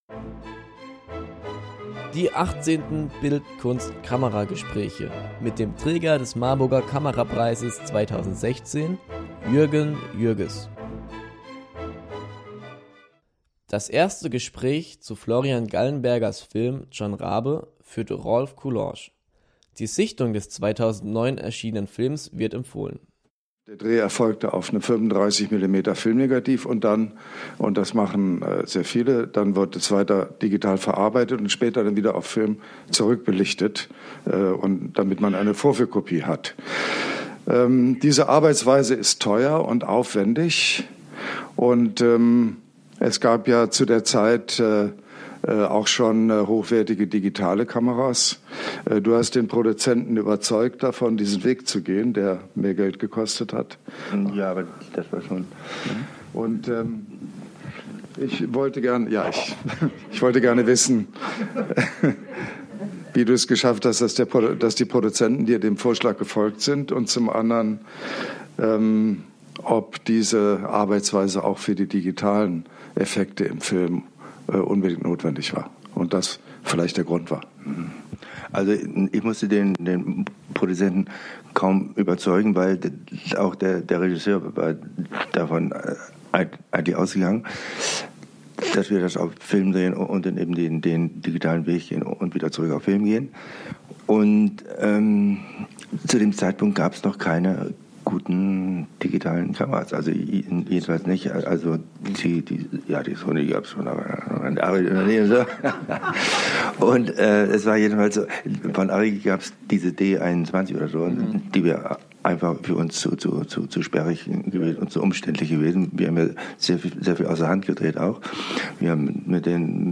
Werkstattgespräch Jürgen Jürges Teil 1 .